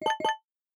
biometric_register_error.ogg